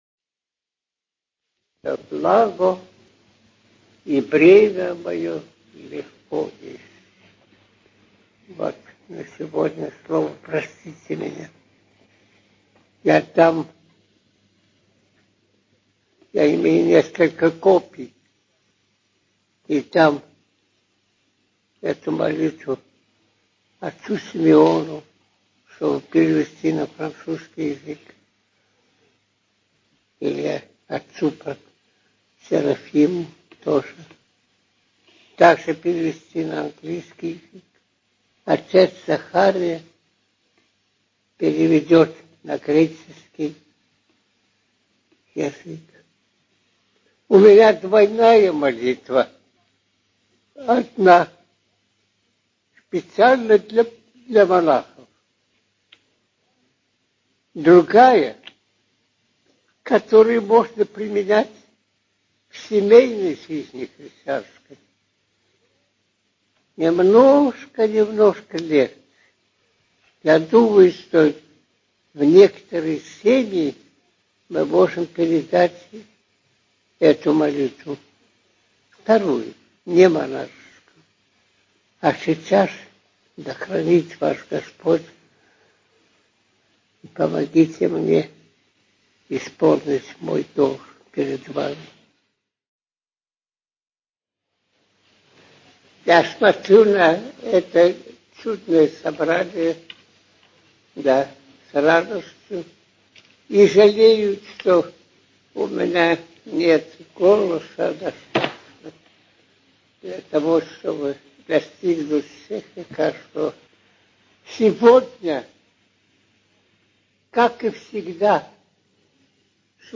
Аудиозапись беседы старца Софрония о молитве «О единстве»
Аудиозапись беседы на которой старец Софроний (Сахаров) предложил слушателям составленную им молитву о единстве, для монастыря и для семьи, подробно прокомментировав ее и призвав всех слушателей к чтению этой молитвы. В конце также добавлена запись чтения этой молитвы в Эссекском монастыре.